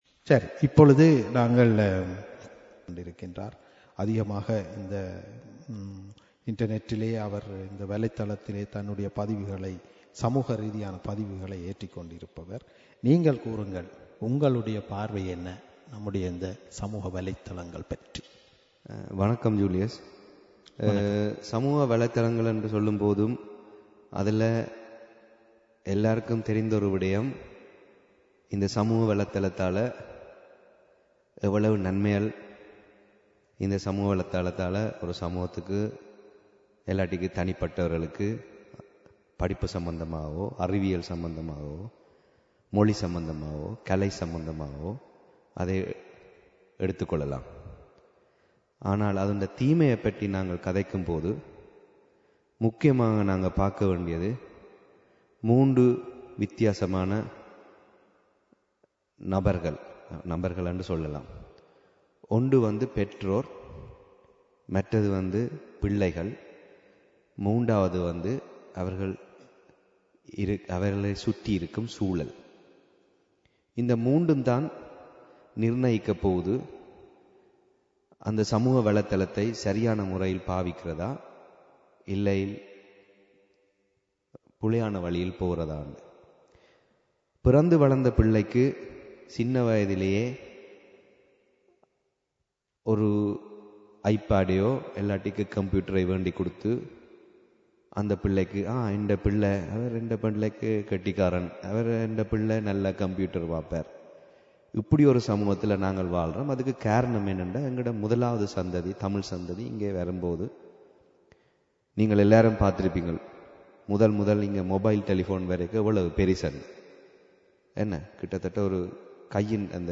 no5-Paneldebatt-2.mp3